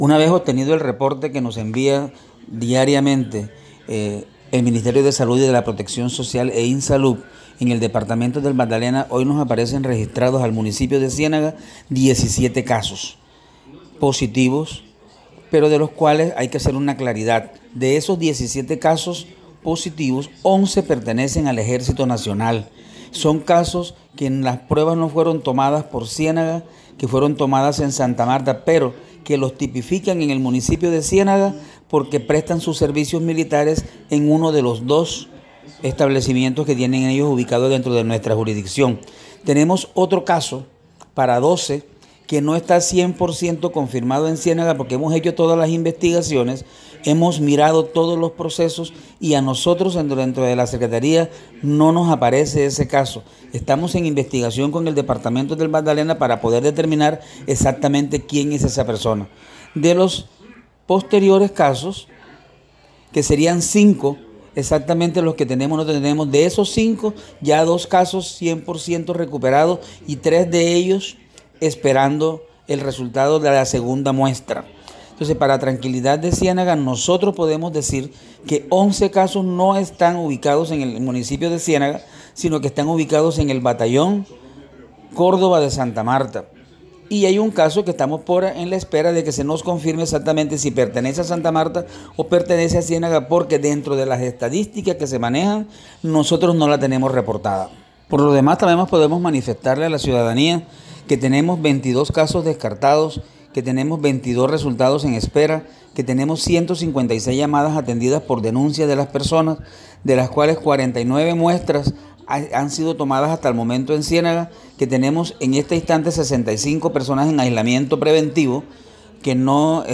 LUIS-FERNANDEZ-QUINTO-SECRETARIO-DE-SALUD.mp3